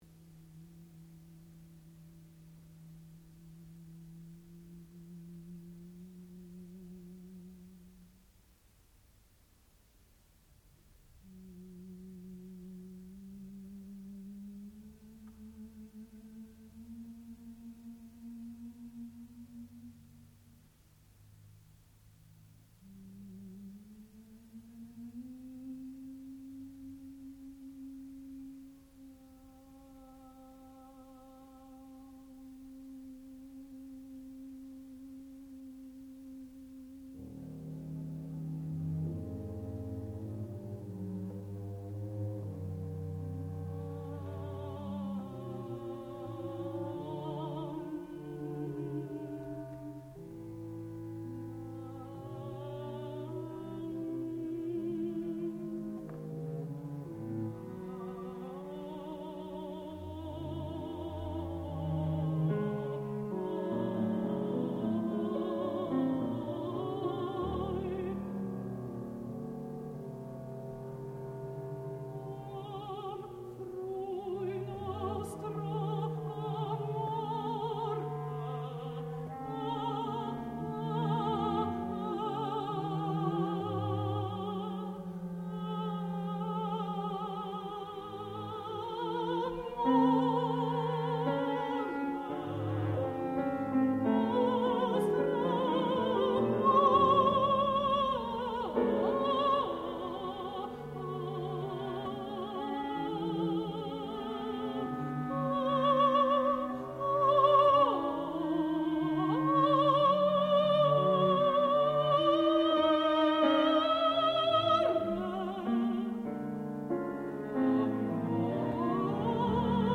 sound recording-musical
classical music
double bass
piano/celesta